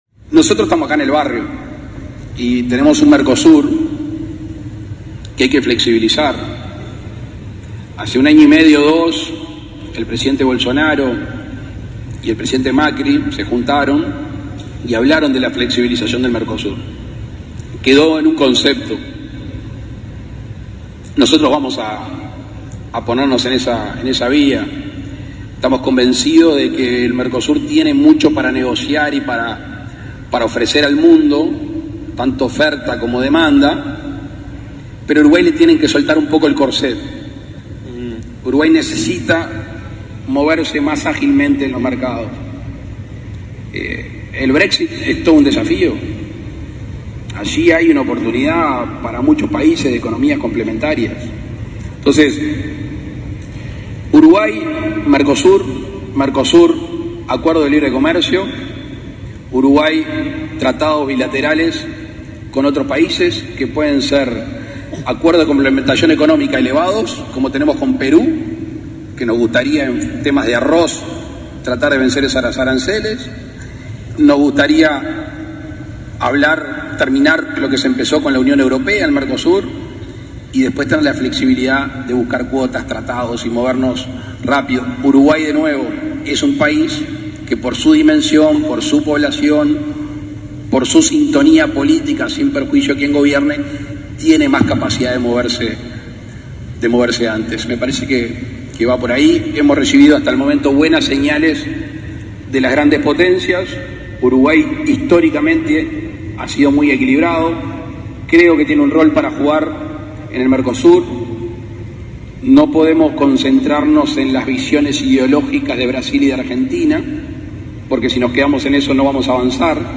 El mandatario electo habló este viernes en el American Business Forum, entrevistado por el pupular conductor estadunidense Ismael Cala, en el Convention Center de Punta del Este, al inicio de la 5ª edición de esta actividad.